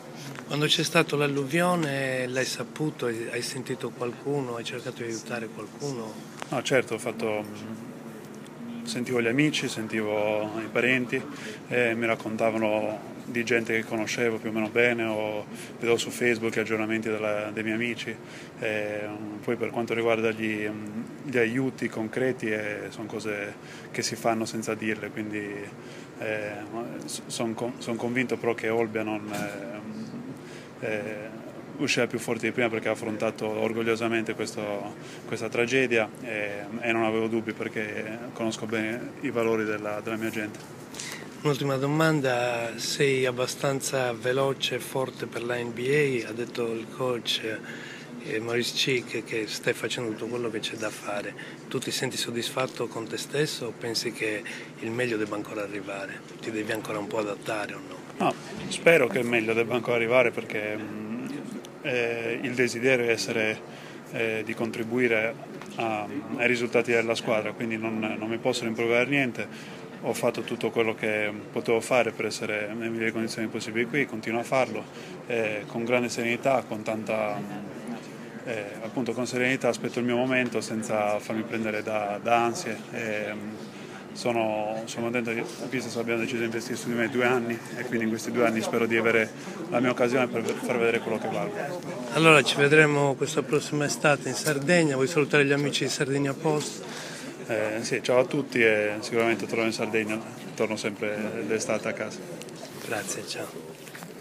Ho atteso a New York un mese che arrivasse l’ok dalla NBA per poterlo incontrare negli spogliatoi del Madison Square Garden prima del suo incontro coi Knicks e lui, con gentilezza e professionalità mi ha parlato di questo suo momento, della sua vita, la sua carriera, la sua sardità il suo dolore per l’alluvione a Olbia.